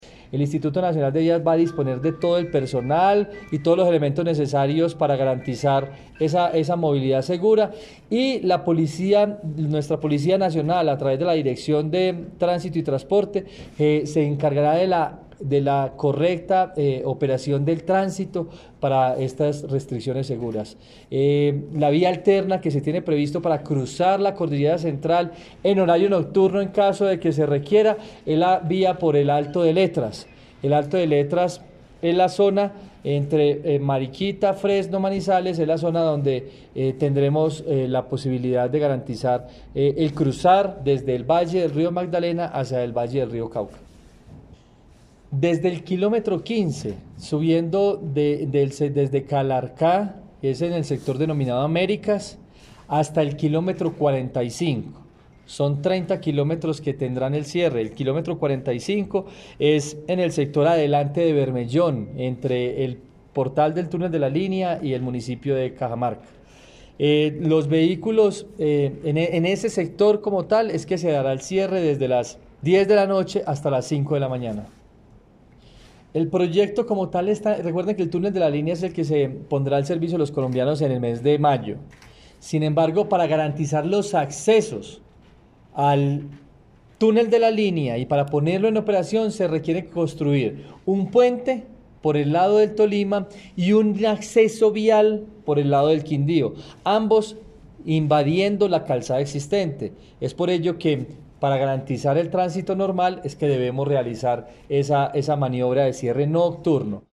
Audios Director General del INVÍAS, Juan Esteban Gil Chavarría